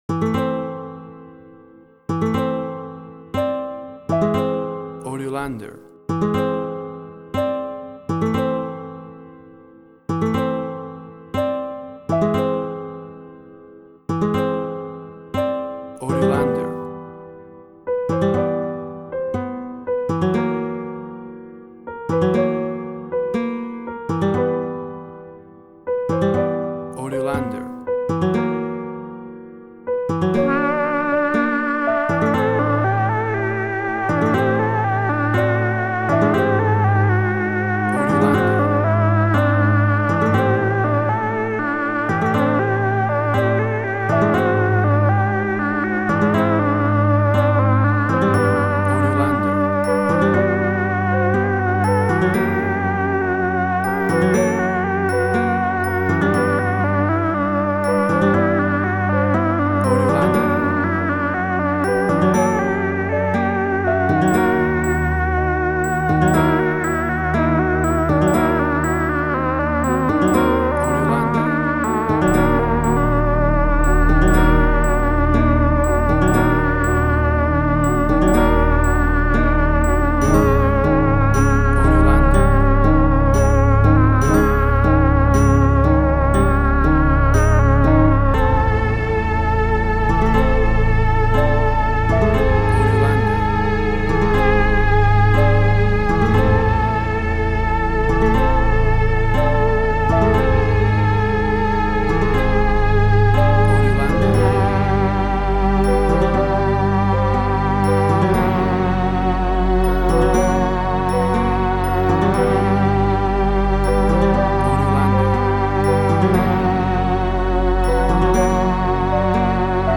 Latin Fusion Orchestral Hybrid
Tempo (BPM): 60